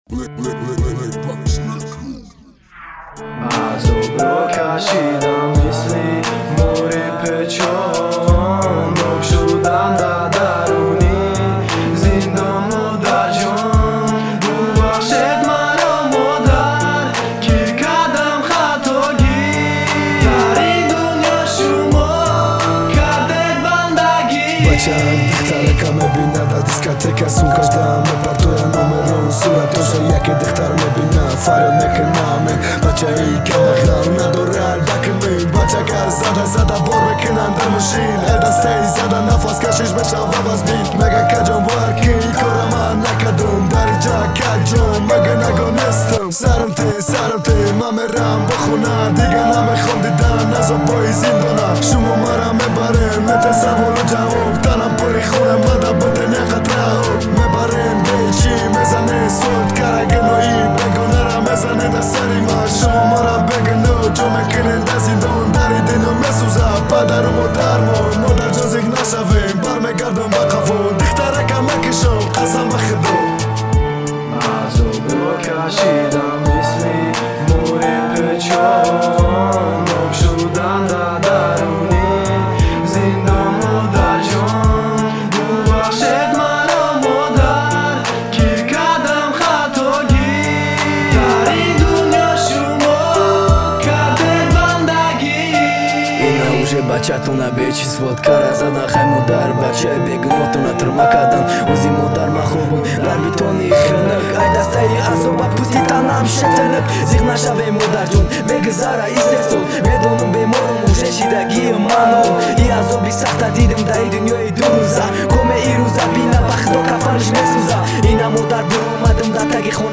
Таджикский рэп Автор